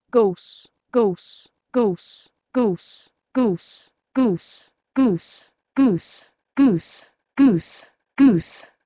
E.g. 6. Old English gōs, "goose", had a mid vowel, but now it's a close, back vowel [u:]:
(In this simulation the vowel of "gōs" is a bit diphthongized, which may not be accurate. Still ...)
goos-to-guus.wav